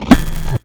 sci-fi_shield_power_deflect_block_02.wav